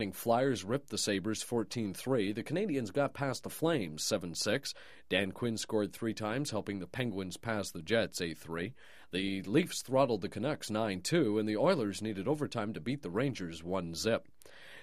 TV Sports Report Announcer